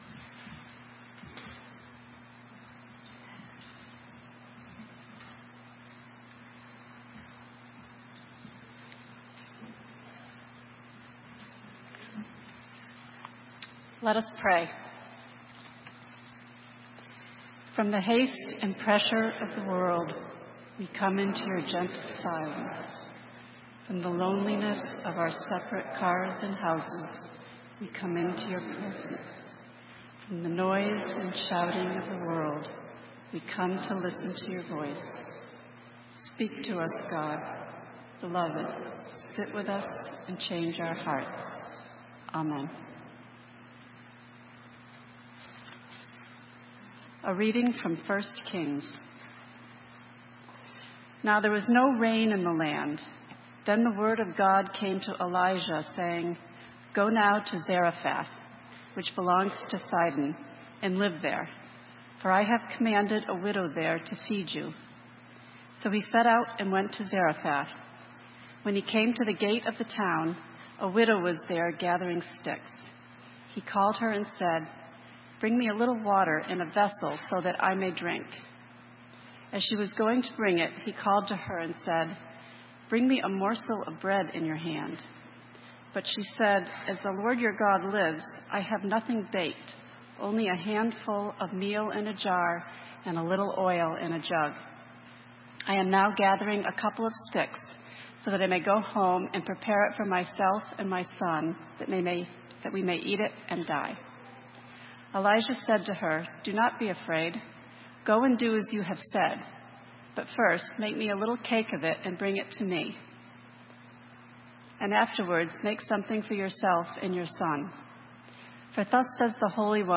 Sermon:A re-creating place - St. Matthews United Methodist Church